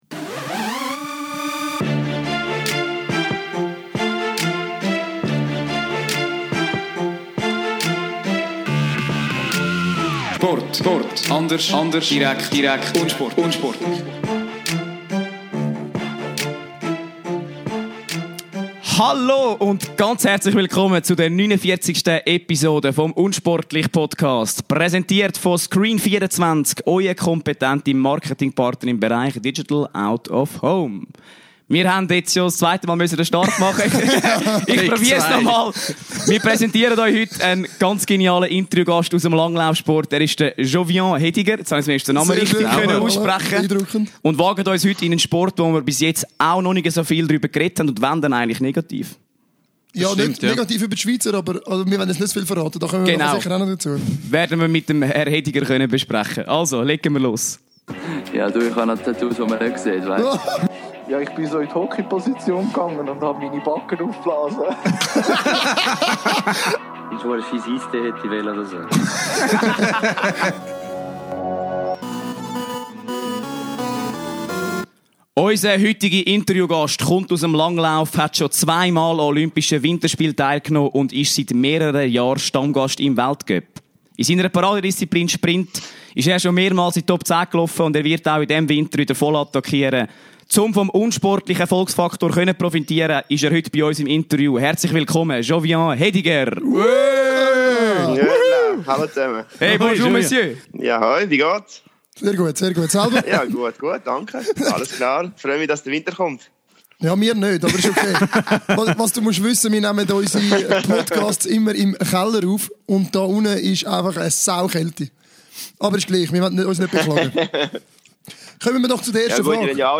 07.11.2018 - Mit Jovian Hediger als Interview-Gast wagt sich unsportlich erstmalig in die Welt des Langlaufs!